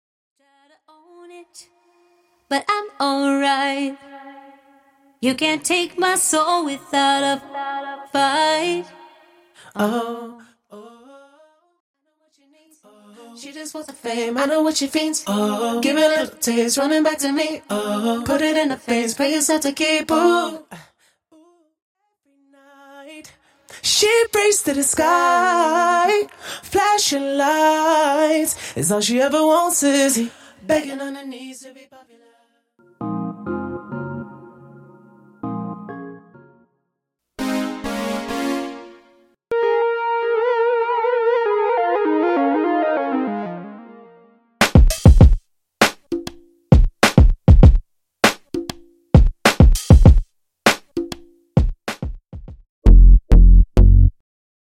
Studio Bassline Stem
Studio Keys & Waves Stem
Studio Percussion & Drums Stem
Studio Synths, FX & Adlibs Stem